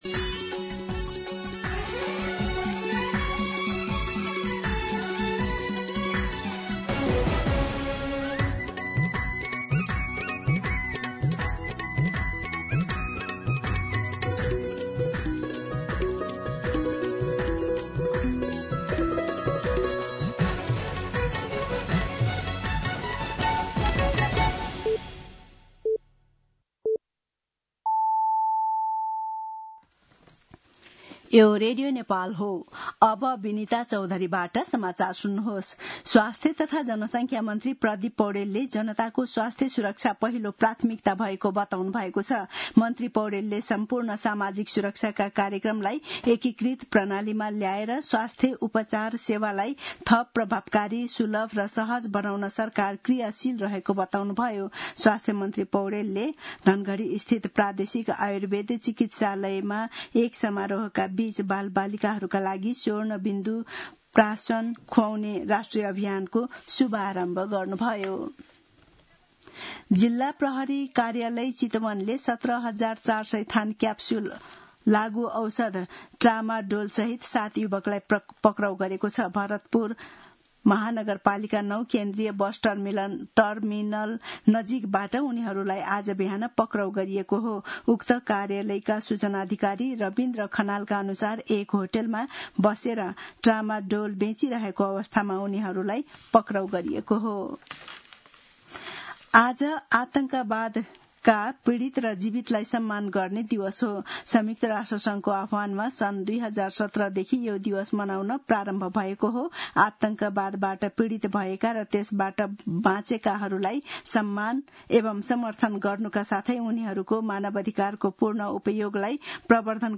मध्यान्ह १२ बजेको नेपाली समाचार : ५ भदौ , २०८२